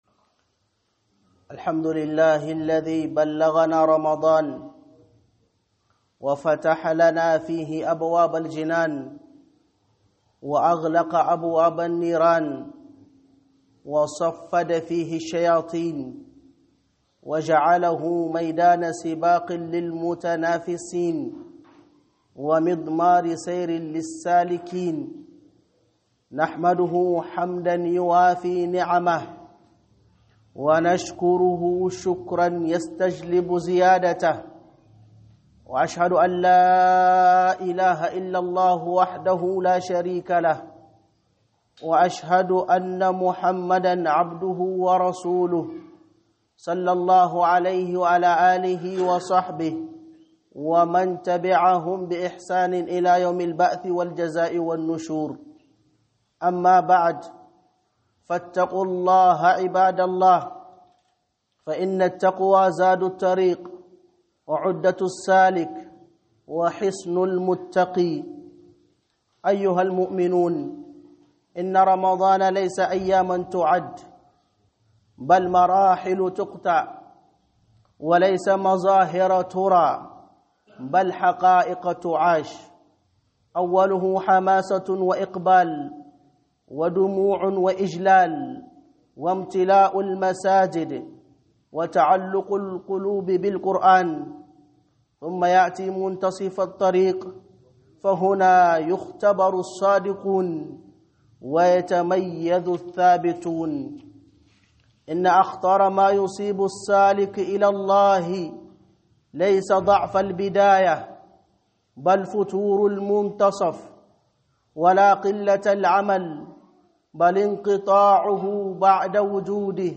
Hudubah (10 Ramadan) - HUƊUBAR JUMA'A